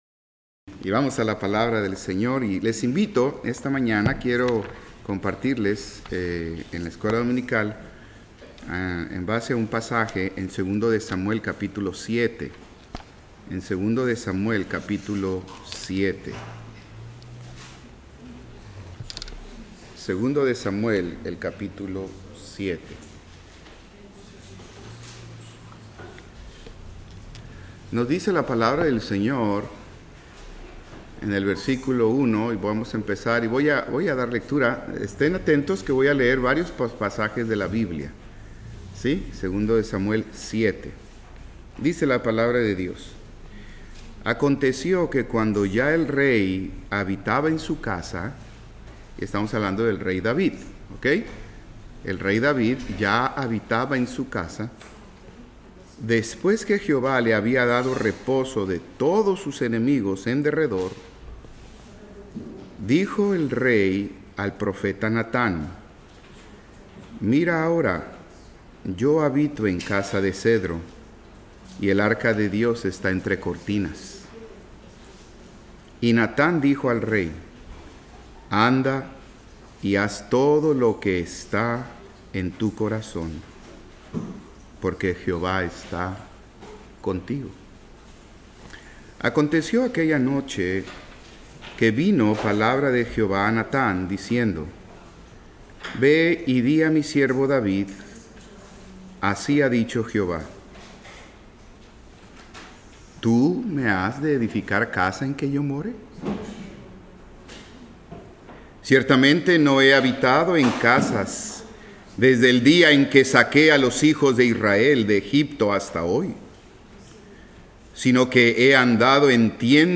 Escuela Dominical